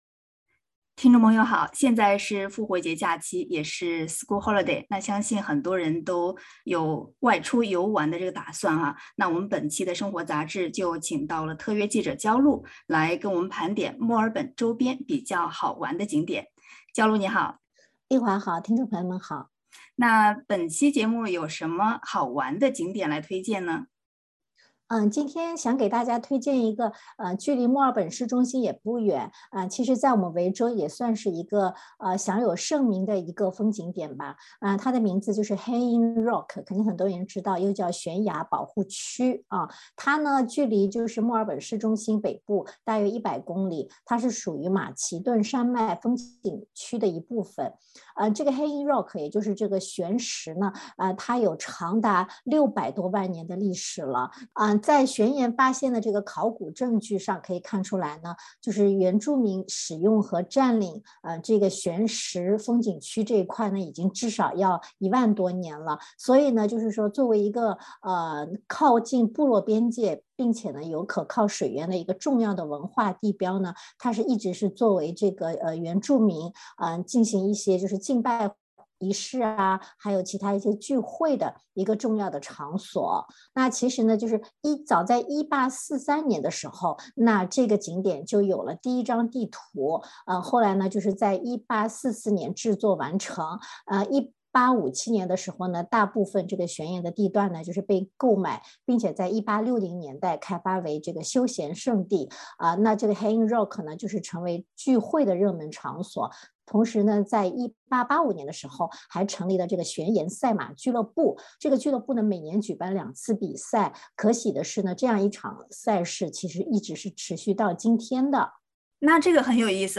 （别忘了钓鱼执照） （点击文首图片，收听完整采访。）